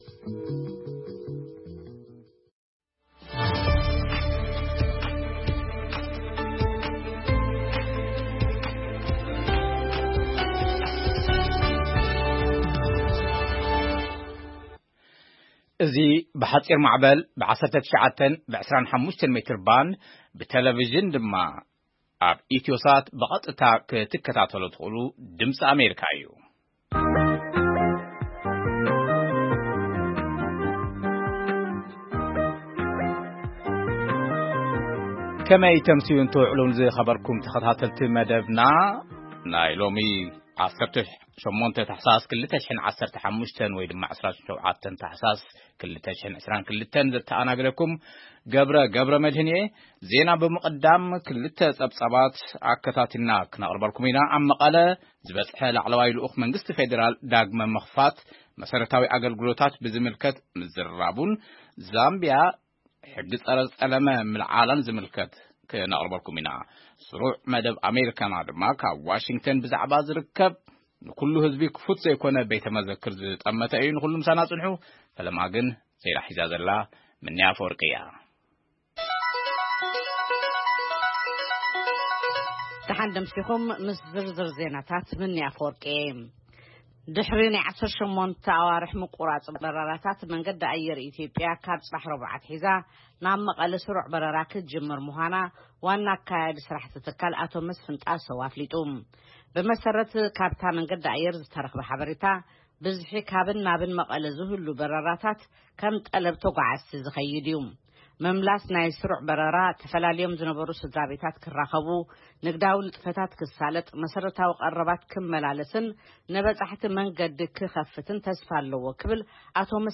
ፈነወ ድምጺ ኣመሪካ ቋንቋ ትግርኛ 27 ታሕሳስ 2022 ዜና ( መንገዲ ኣየር ኢትዮጵያ ካብ ጽባሕ ረቡዕ ናብ መቐለ በረራ ከምዝጅምር ሓቢሩ: ውጥረት ታይዋንን ቻይናን ኣብ ሶማሊላንድ ዘጋጠመ ደርቂን ካልኦትን) መደብ ኣመሪካና የጠቓልል